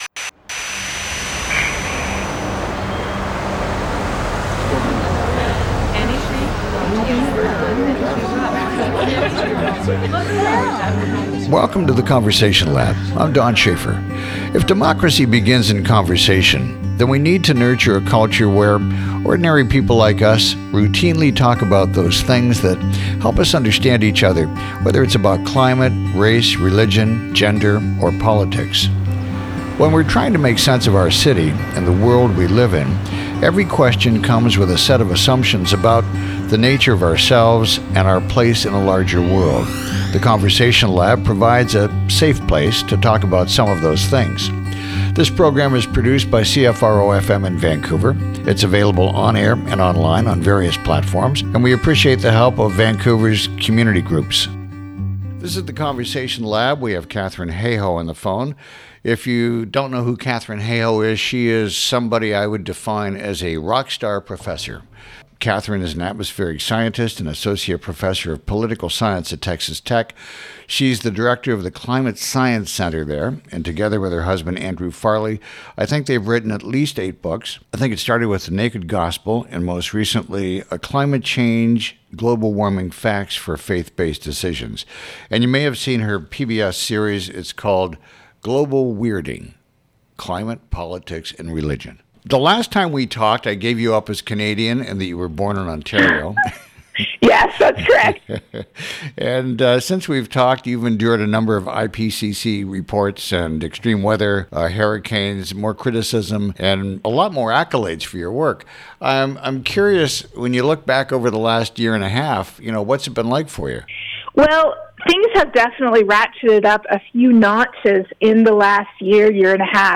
A conversation with Katharine Hayhoe about Climate Change and how we talk about it!